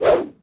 footswing1.ogg